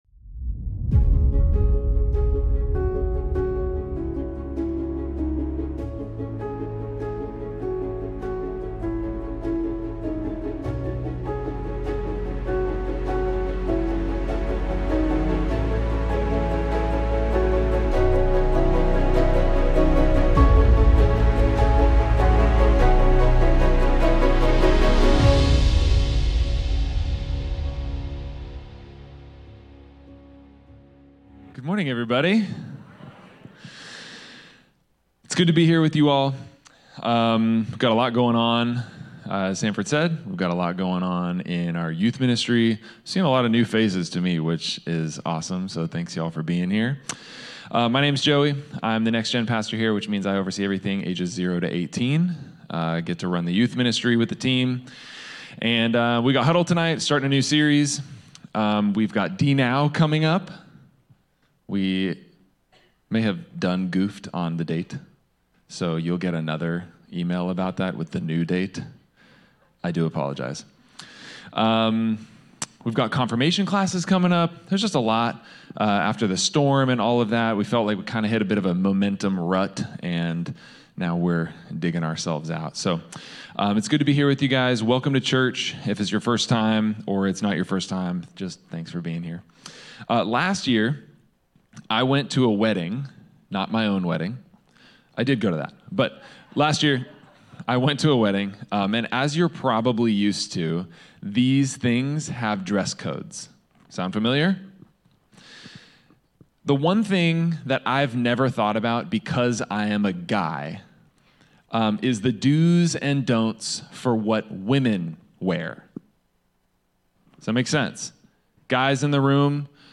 Sermons | Advent Presbyterian Church